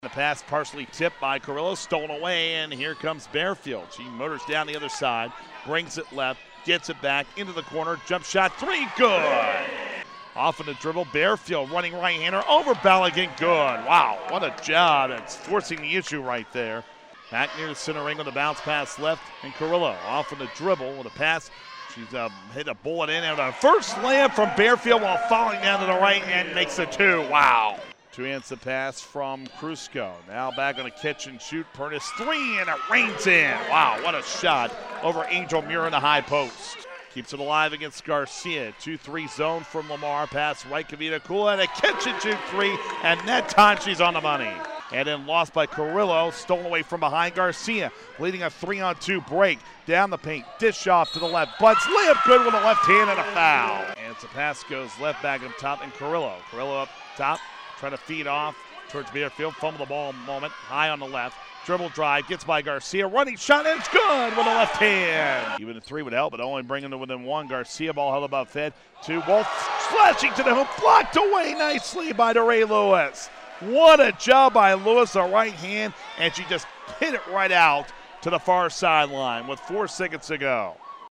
Women’s Highlights Second Half
gccc-lamar-highlights-second-half.mp3